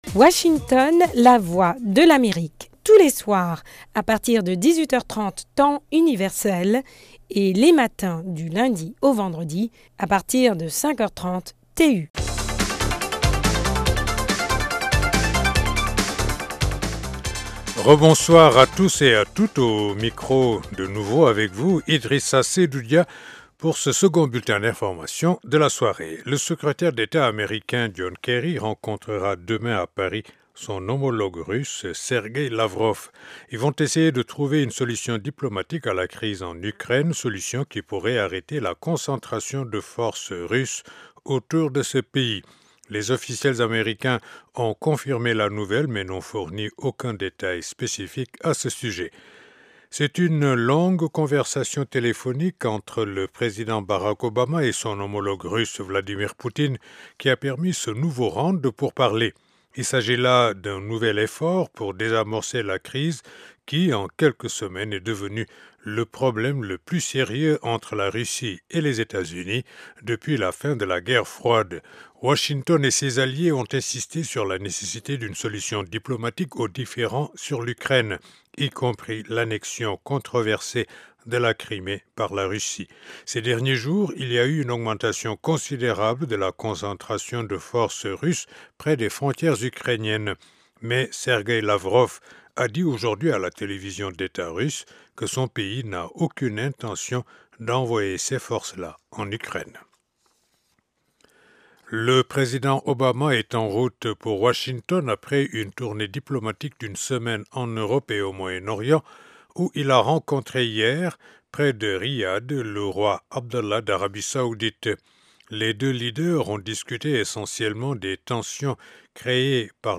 Le Magazine au Féminin a trois invités dans cette édition